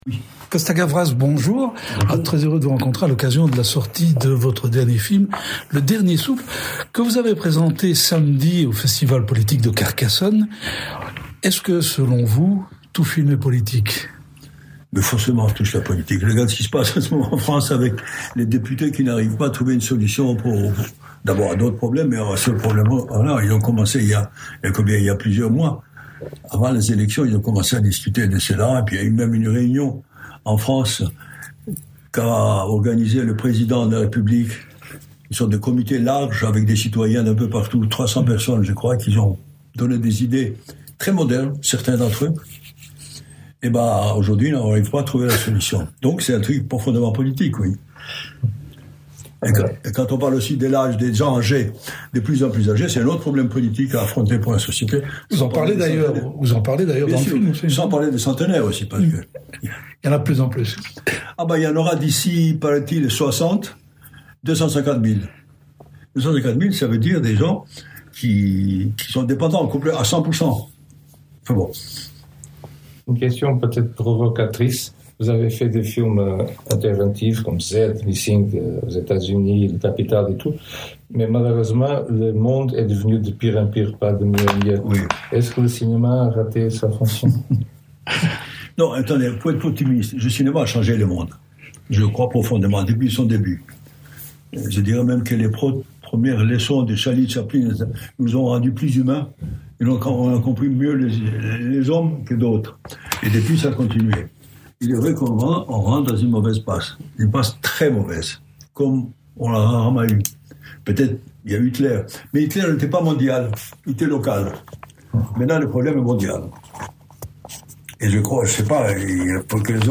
Rencontre avec le réalisateur (et toujours Président de la Cinémathèque de Paris!).«